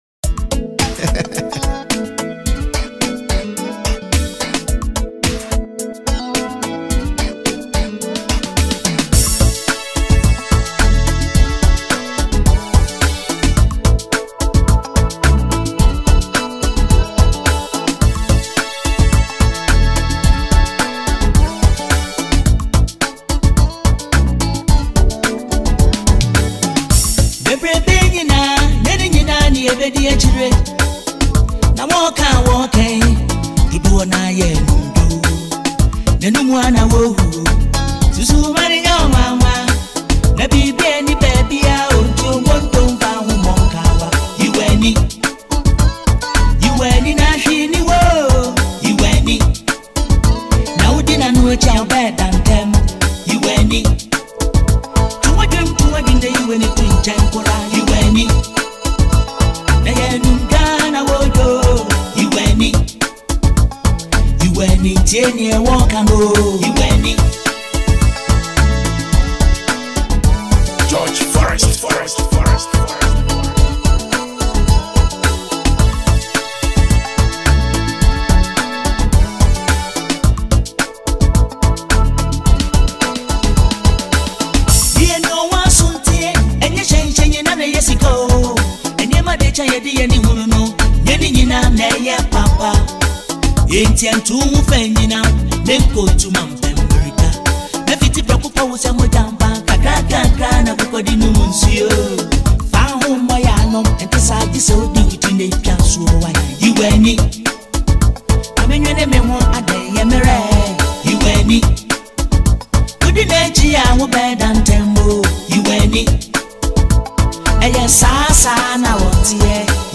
highlife
Known for his soothing voice and timeless storytelling
In this inspirational highlife track